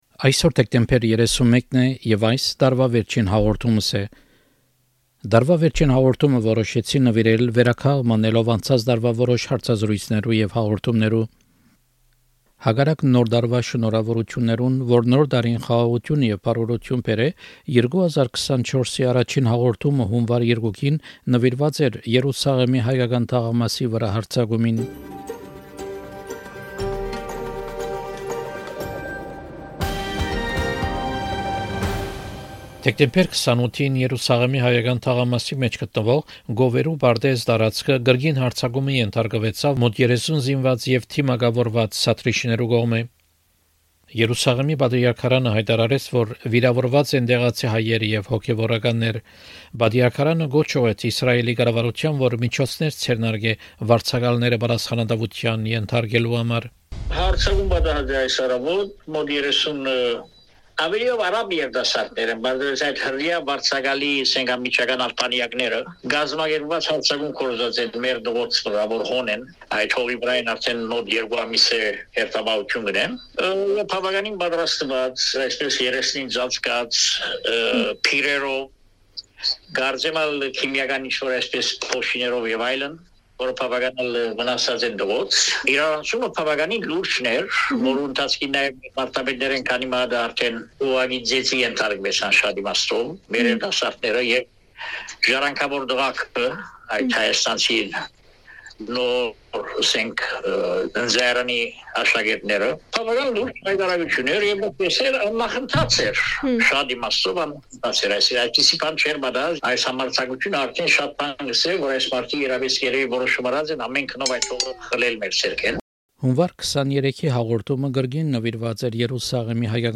Selected interviews from 2024.